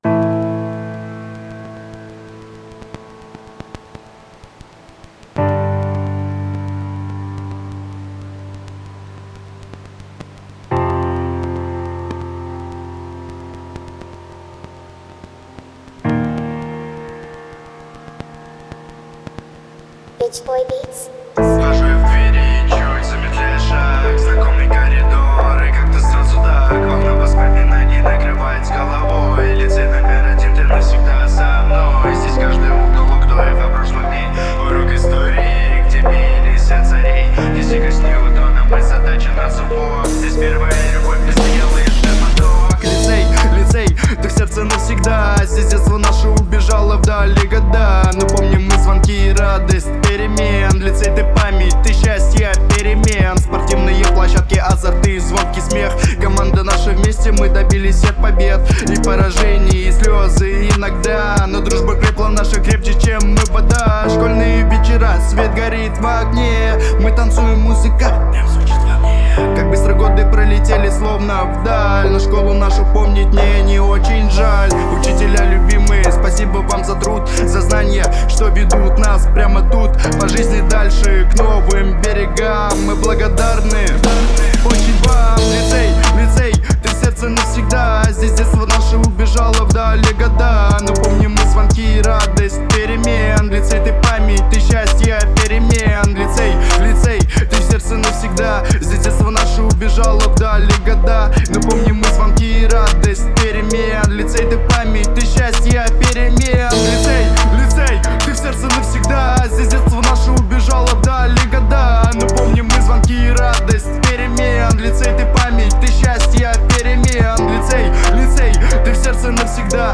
работающий в жанрах реп и хип-хоп.